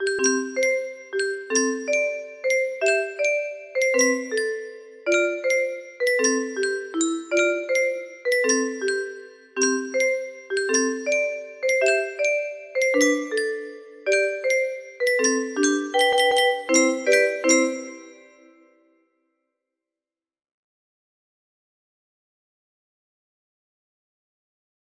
30633 music box melody